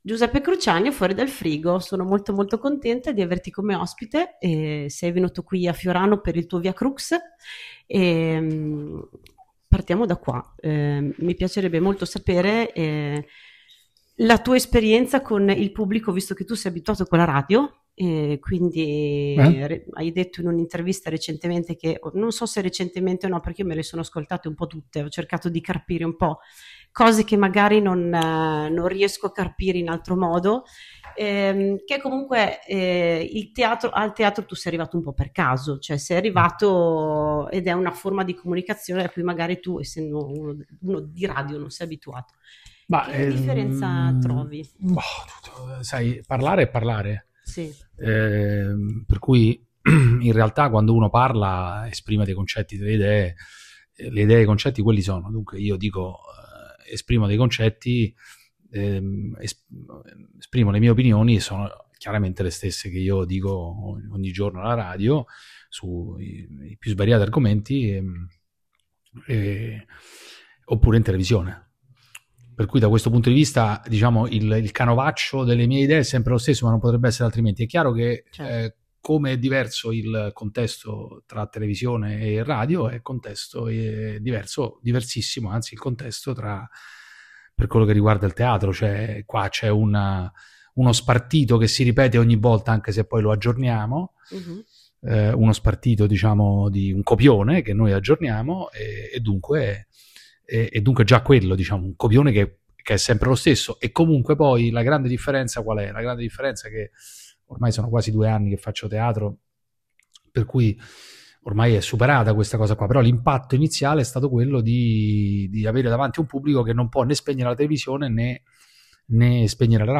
Giuseppe Cruciani intervistato